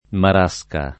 marasca [ mar #S ka ]